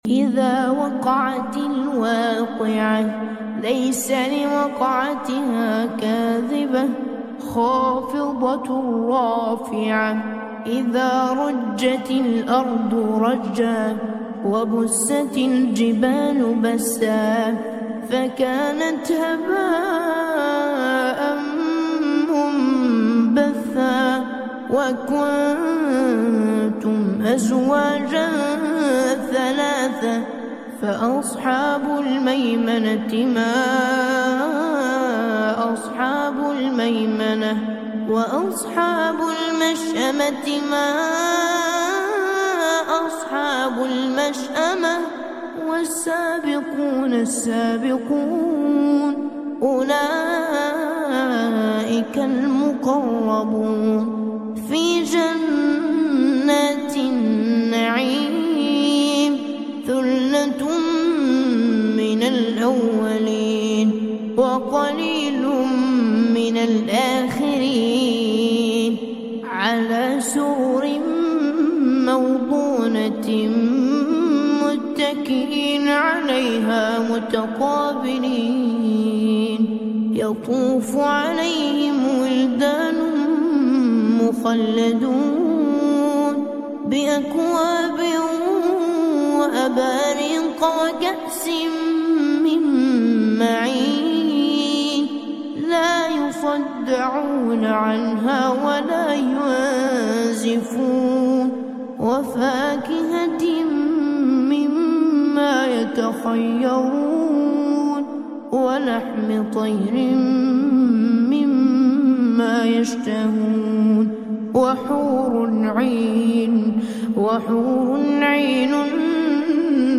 A recitation that relaxes the heart and mind.